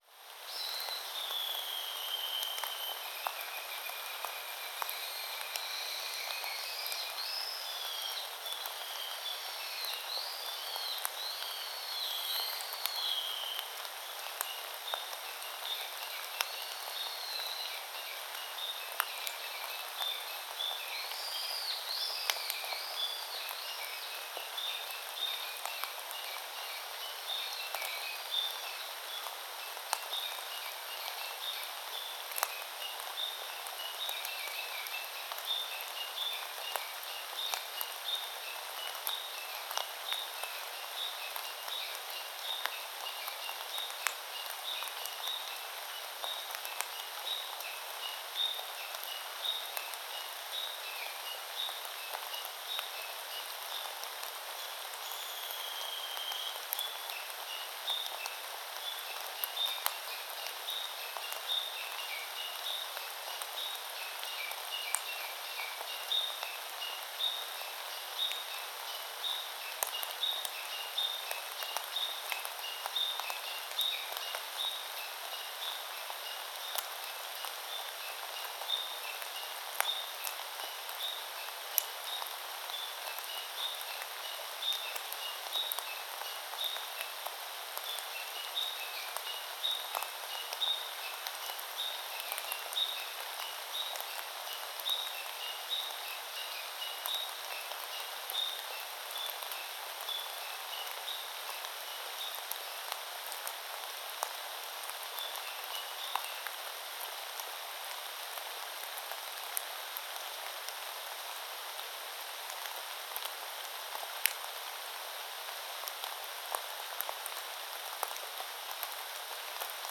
四季の森公園 - 雨05B(HPL2 バイノーラル)